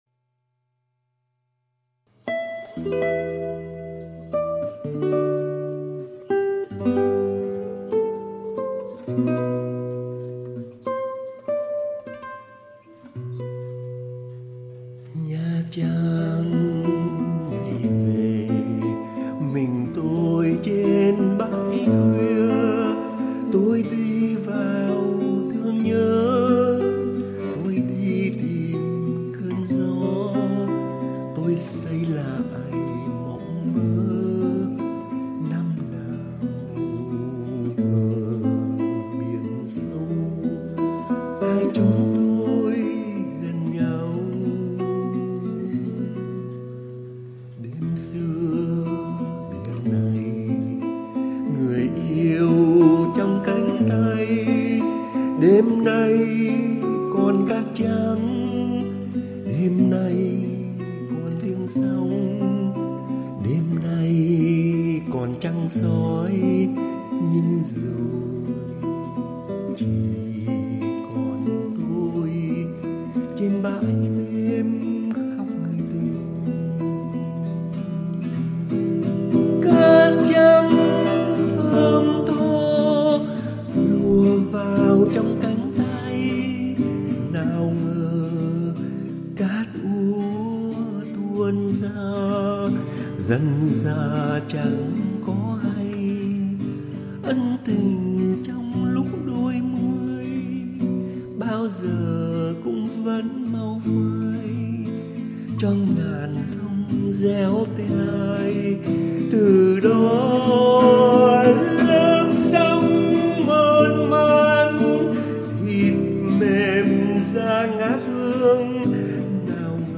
đàn và hát